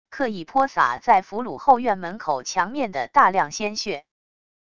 刻意泼洒在俘虏后院门口墙面的大量鲜血wav音频